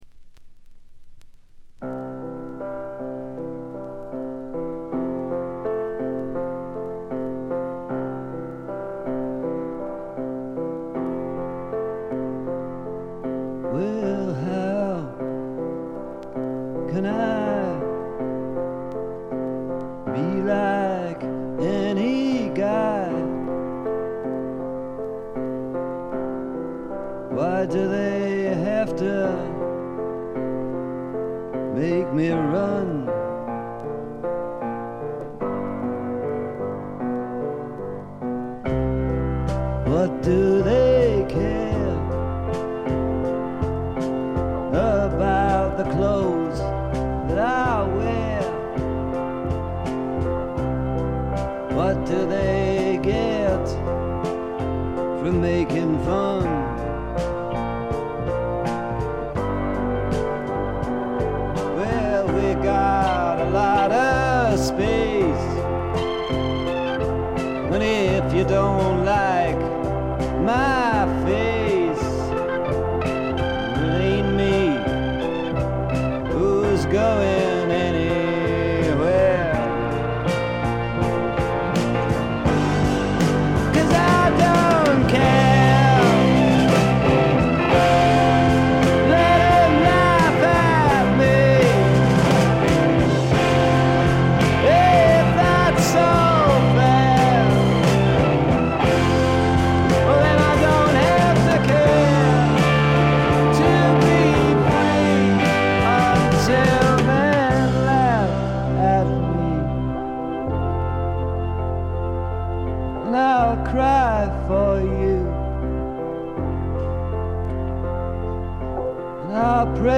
部分試聴ですが、微細なノイズ感のみ、極めて良好に鑑賞できると思います。
試聴曲は現品からの取り込み音源です。